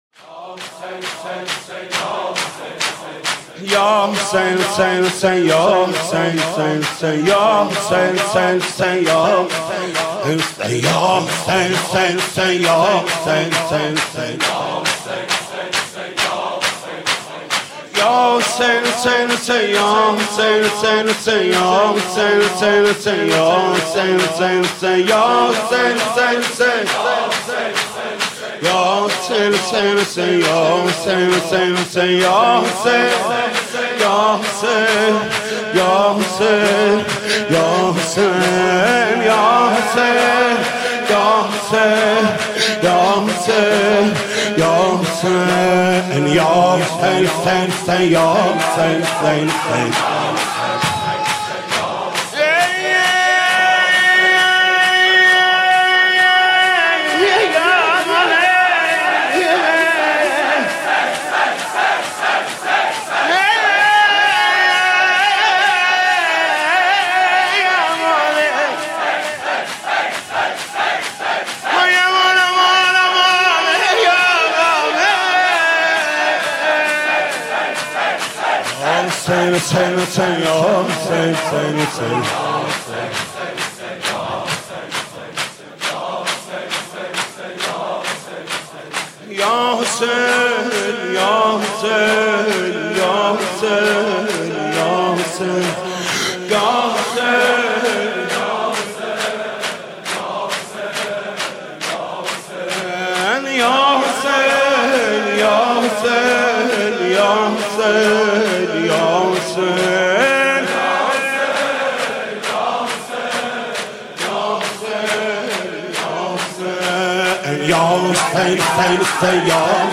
مداحی و نوحه
سینه زنی، شهادت حضرت زهرا(س